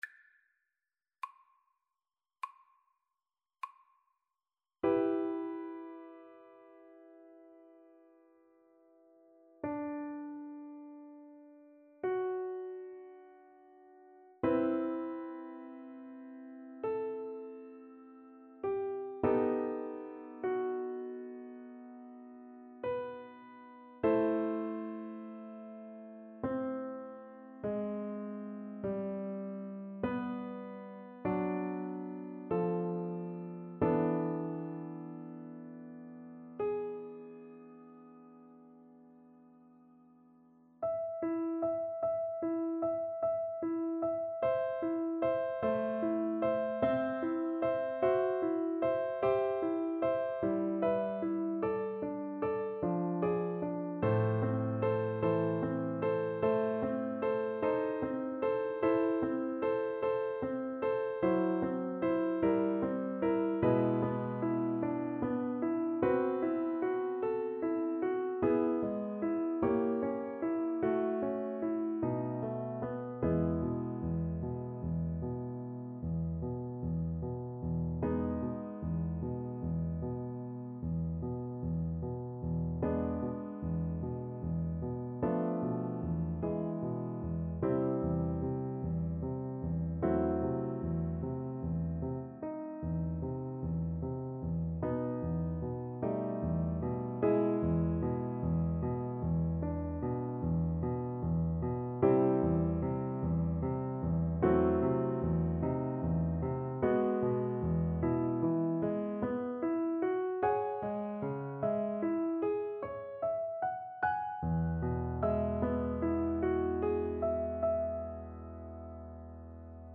Play (or use space bar on your keyboard) Pause Music Playalong - Piano Accompaniment Playalong Band Accompaniment not yet available reset tempo print settings full screen
~ = 100 Lento =50
G major (Sounding Pitch) (View more G major Music for Voice )
Classical (View more Classical Voice Music)